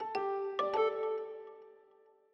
Longhorn Ten Alfa - Notify Messaging.wav